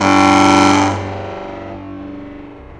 alarm9.wav